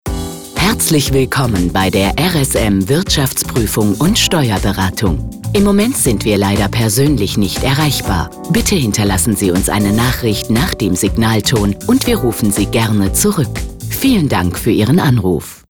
Beispiel: Anrufbeantworter außerhalb der Geschäftszeiten:
Telefonansage Steuerberatung – Deutsch:
RSM-Version-A-mit-Anrufbeantworter-DE.mp3